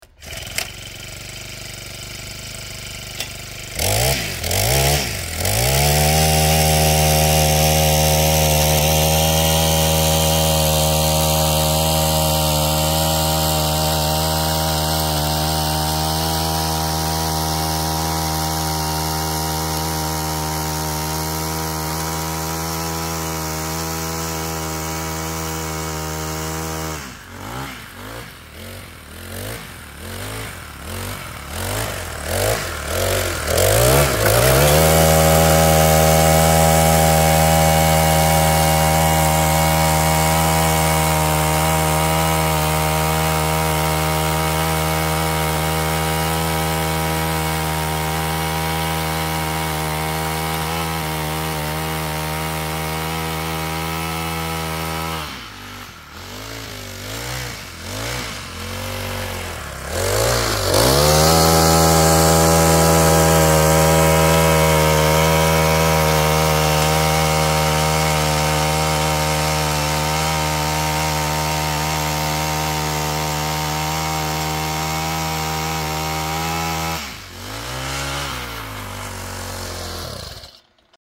Звук компактного тепличного мотокультиватора